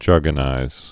(järgə-nīz)